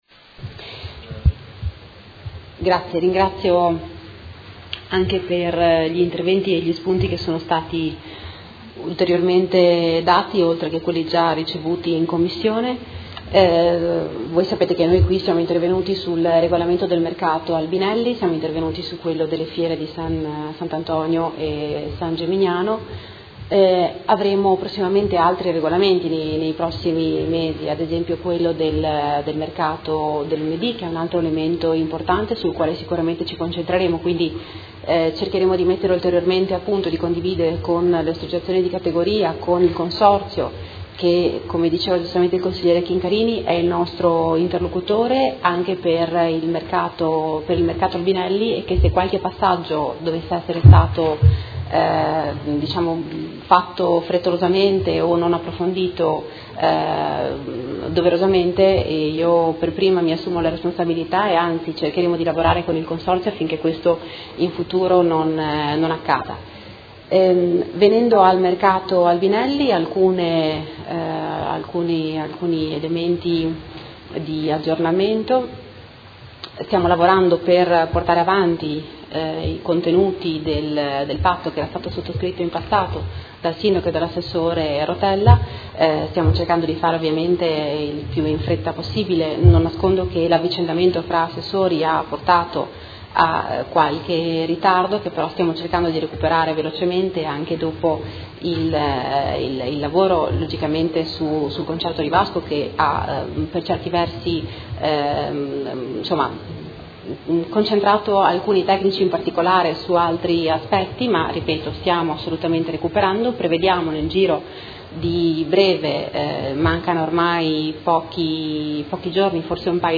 Seduta del 20/07/2017 Conclusione a Dibattito. Delibera: Regolamento Mercato Coperto Albinelli – Approvazione modifiche e Delibera: Regolamento della Fiera di S. Antonio e San Geminiano – Approvazione modifiche